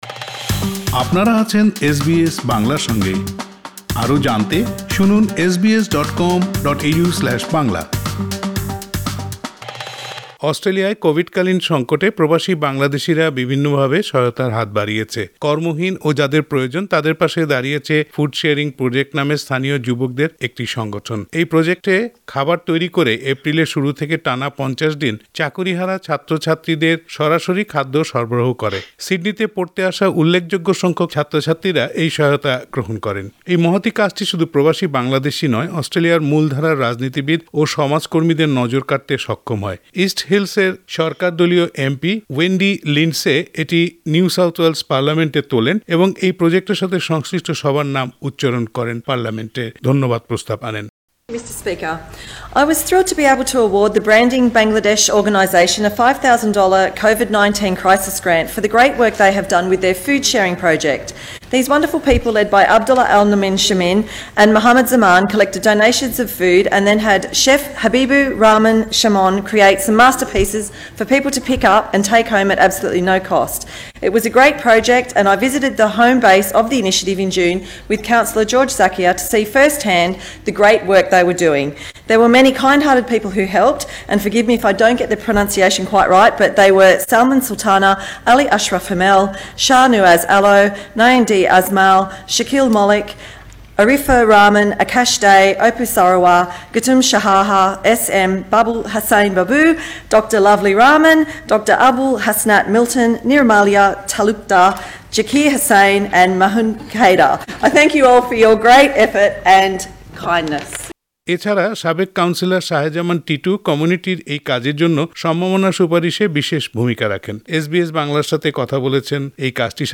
এসবিএস বাংলা কথা বলেছে এই কাজটির সাথে যুক্ত কয়েকজনের সাথে।